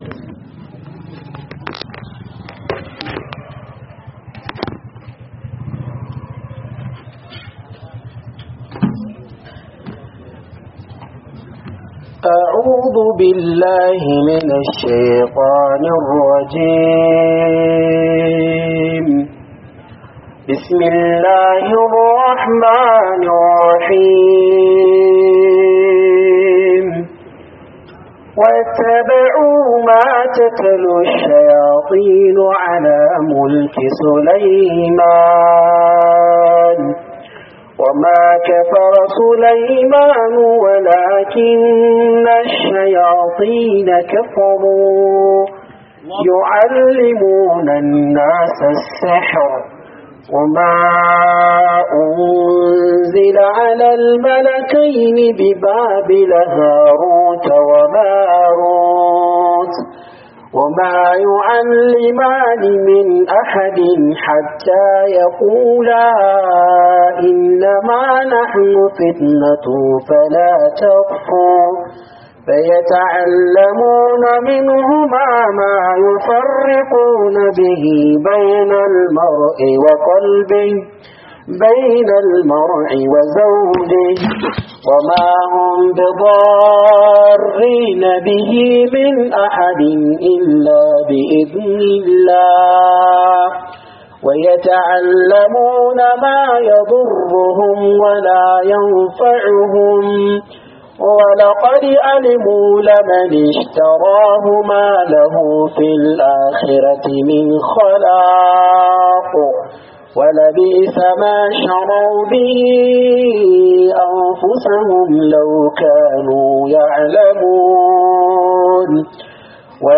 Falalar biyayya ga iyaye - Muhadara